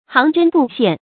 行针步线 xíng zhēn bù xiàn
行针步线发音
成语注音 ㄒㄧㄥˊ ㄓㄣ ㄅㄨˋ ㄒㄧㄢˋ